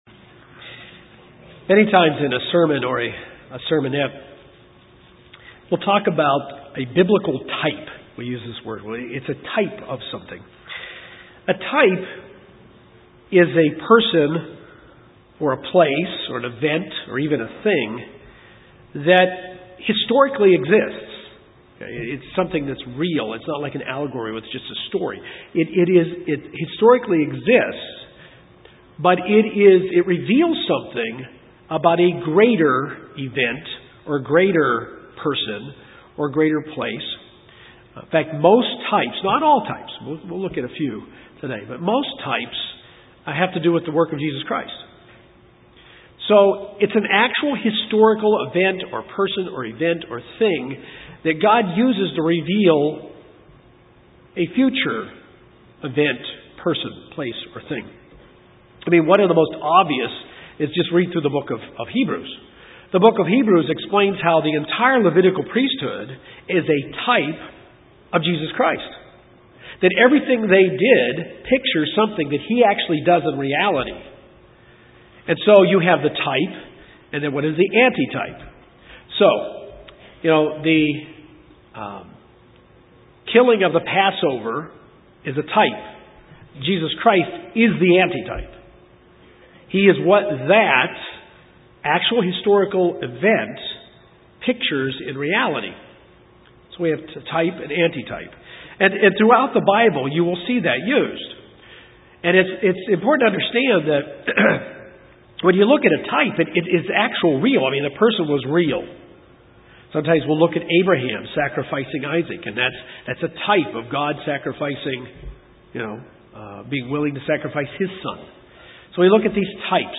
The ceremony of the Passover that we keep today in the church is nothing like the Passover of the Old Testament Passover. In this sermon we learn of the types and anti-types of the Passover to come to a better understanding of why we keep the Passover the way we do in the church today.